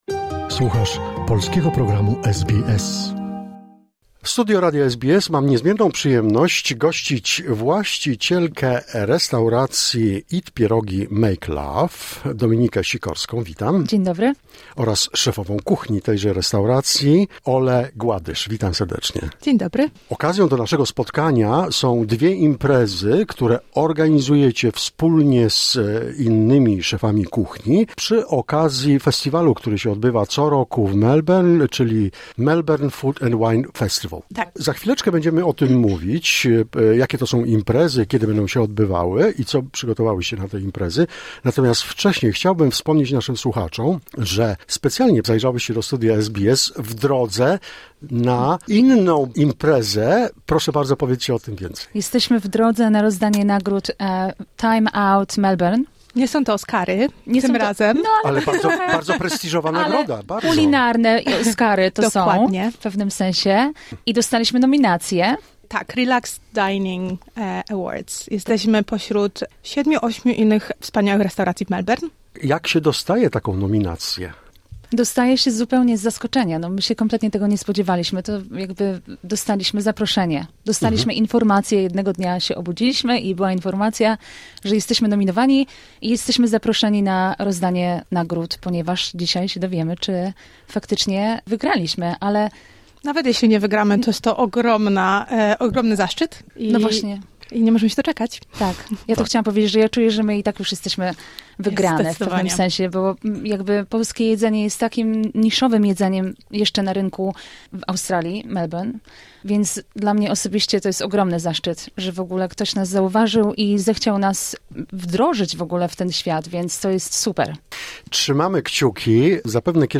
Studio SBS w Melbourne